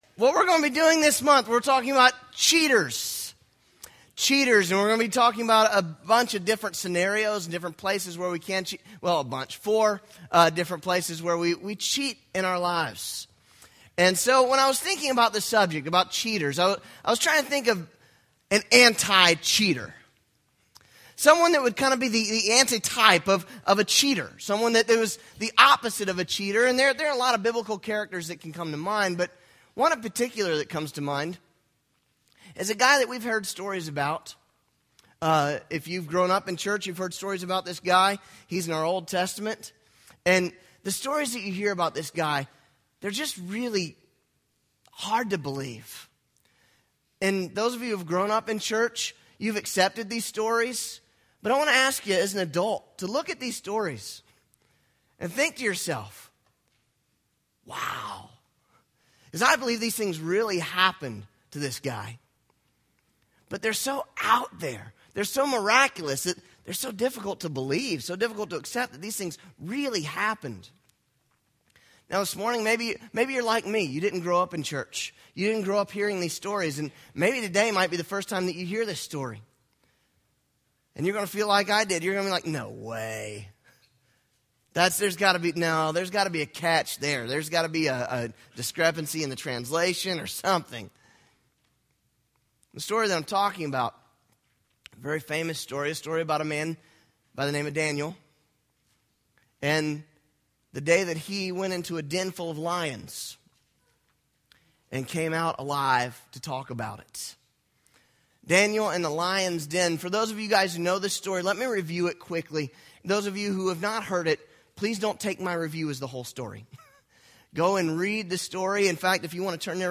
February 1 - Cheating Our Work Right Click to download this sermon